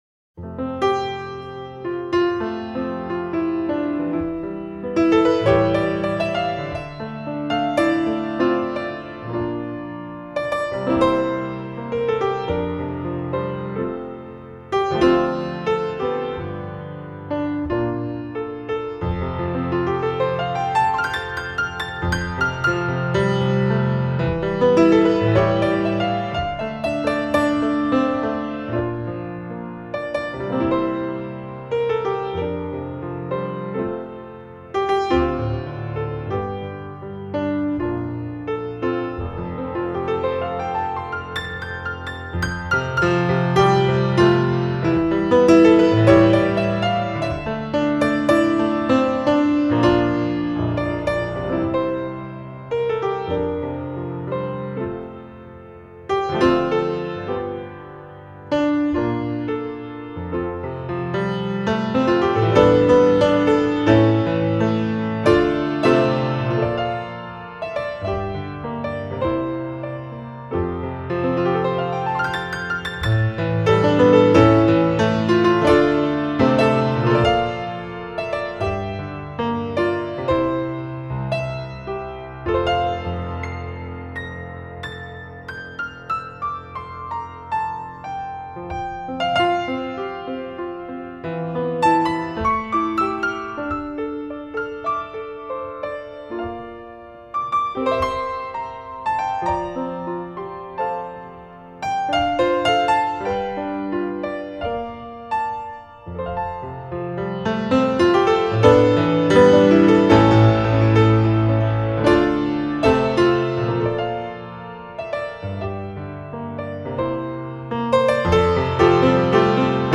موسیقی کنار تو
آرامش بخش پیانو موسیقی بی کلام